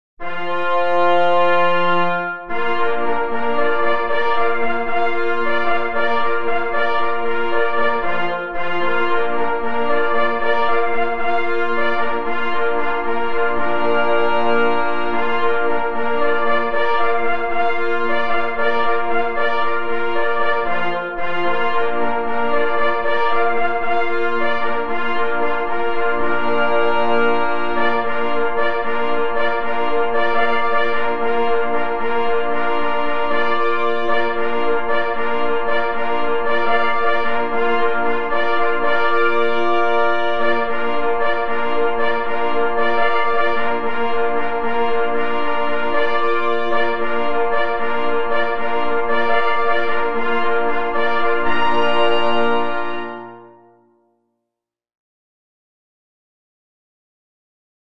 na 2 plesy i 2 parforsy Tytuł utworu: Fanfara
Gatunek utworu: fanfara Strój: B